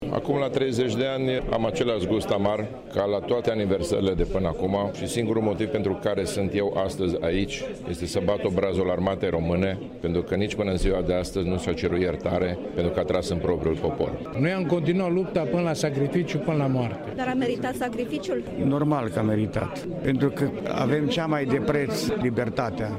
vox-timisoara-revolutie-30-de-ani.mp3